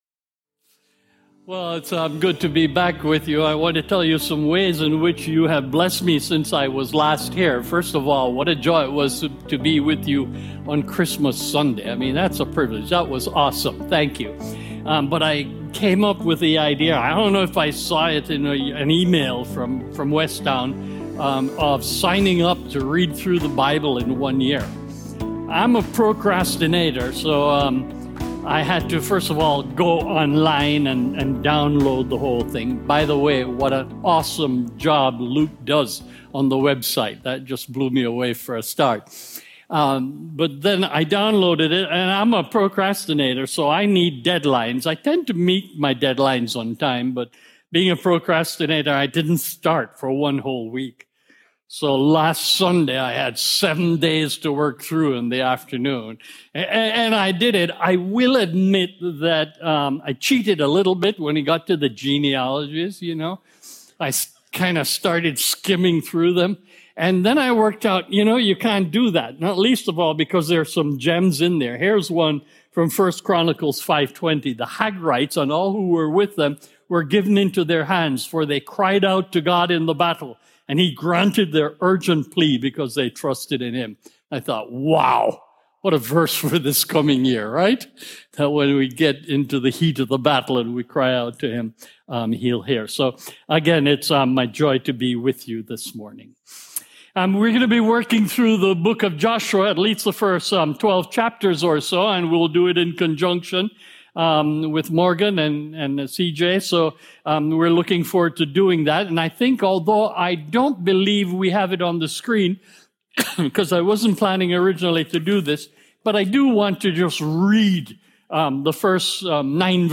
Join us in this 13 week sermon series through Joshua and discover what it looks like to find our strength and courage in the Lord!